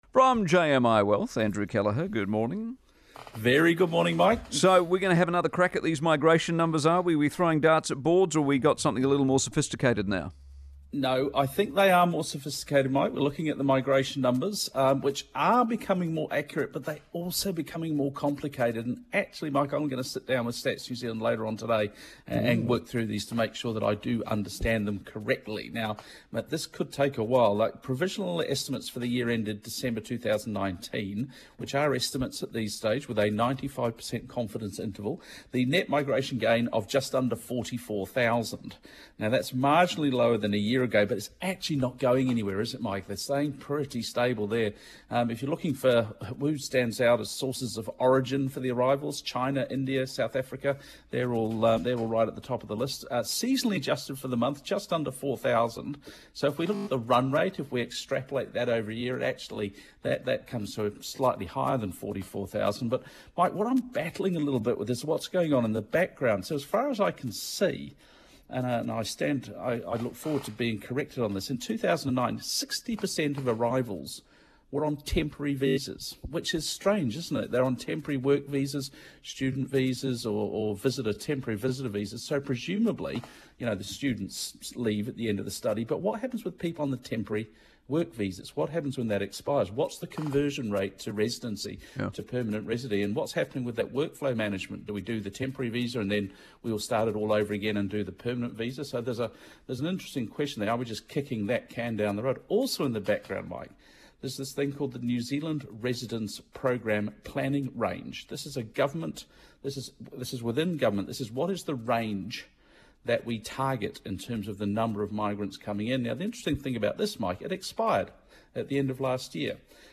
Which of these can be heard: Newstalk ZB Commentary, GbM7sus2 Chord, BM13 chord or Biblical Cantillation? Newstalk ZB Commentary